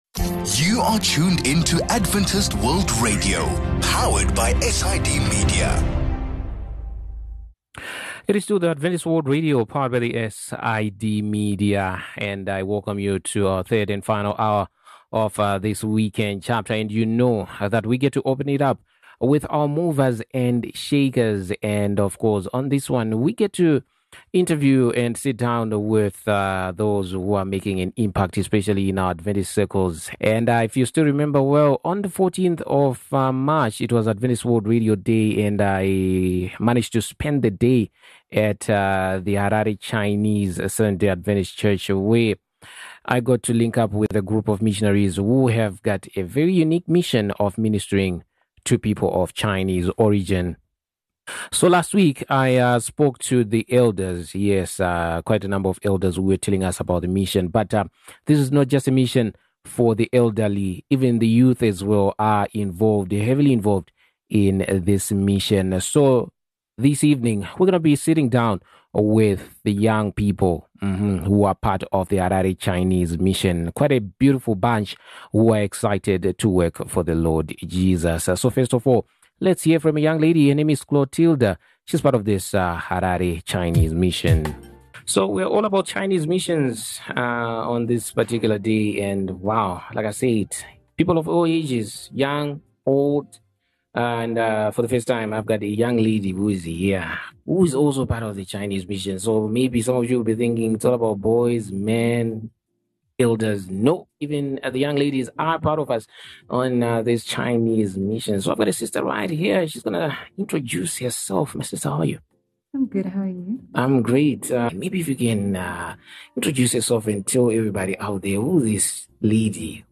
The interview with young missionaries from Harare Chinese Seventh-day Adventist Church on Movers and Shakers explores their passion for mission work, cultural identity, challenges, and experiences sharing their faith. They share personal stories and insights into their unique mission, offering a glimpse into their lives and work.